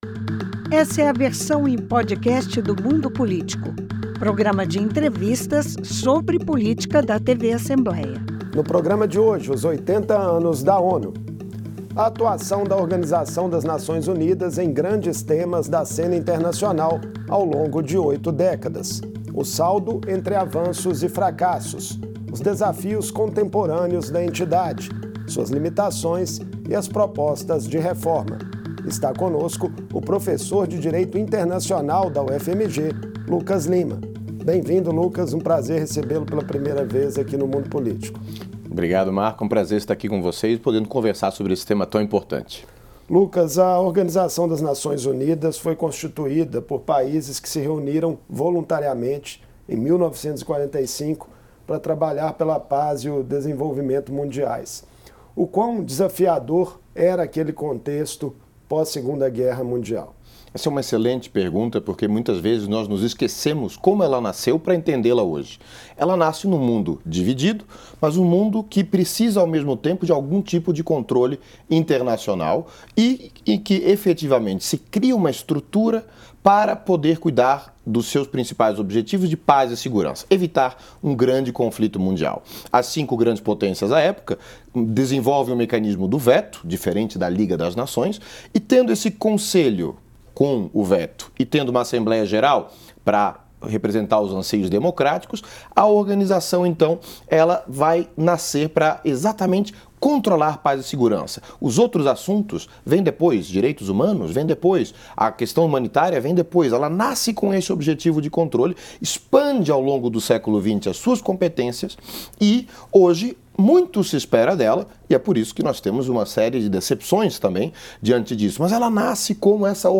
A Organização das Nações Unidas completa oito décadas em 26 de junho de 2025 e surge como um organismo que vai buscar os consensos entre os estados nacionais donos do poder. Em entrevista